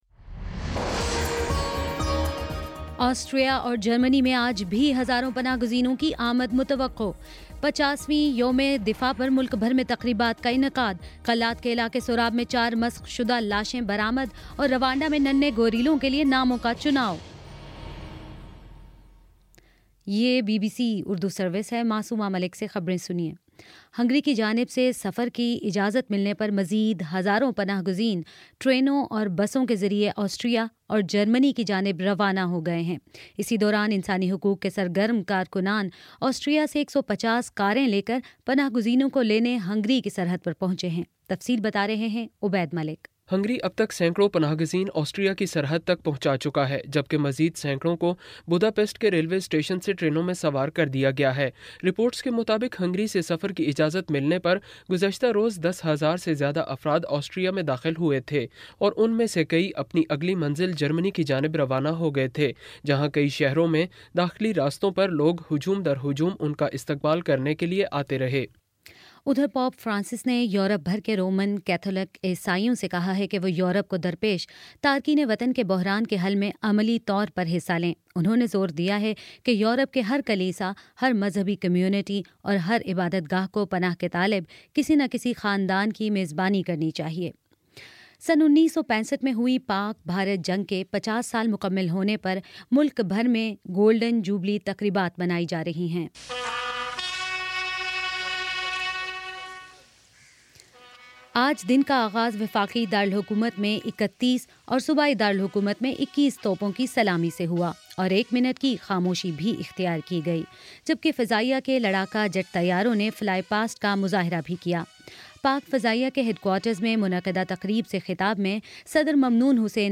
ستمبر6 : شام سات بجے کا نیوز بُلیٹن